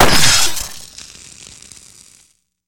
stungun.wav